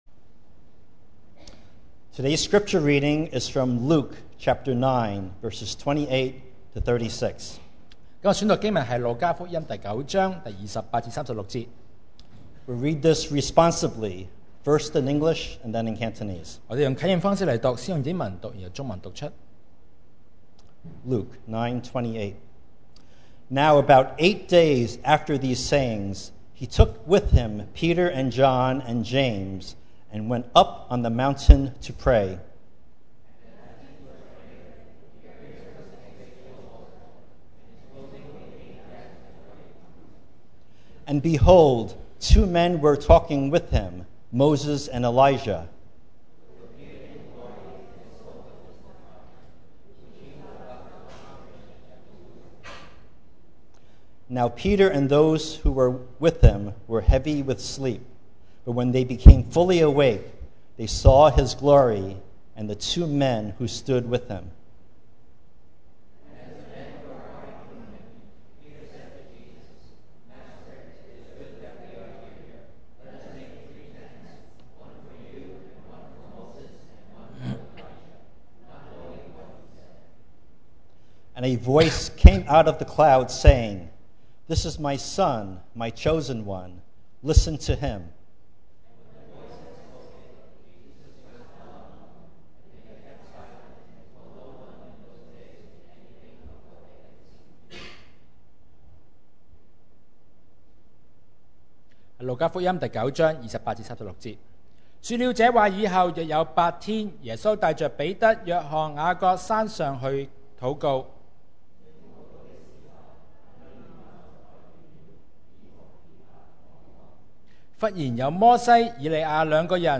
Series: 2019 sermon audios
Service Type: Sunday Morning